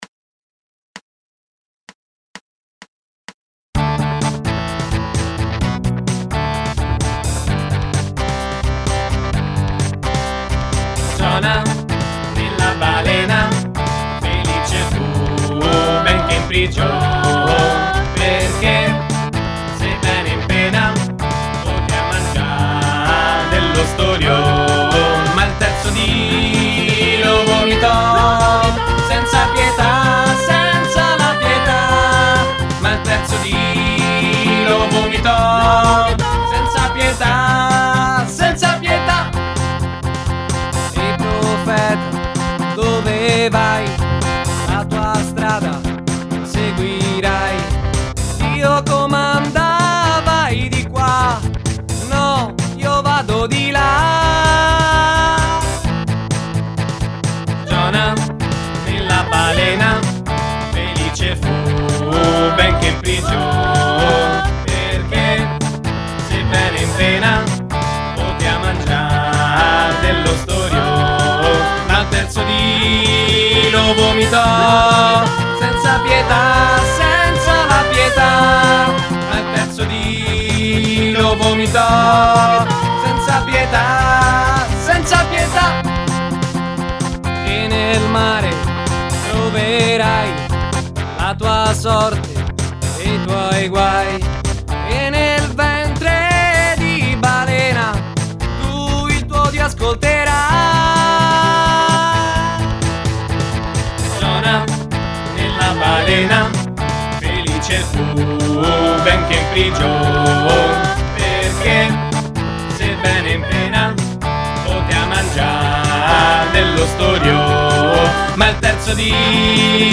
Eseguito dal gruppo animatori della parrocchia San Gregorio Magno l'inno del campo Dopocresima 2009 Giona nella Balena.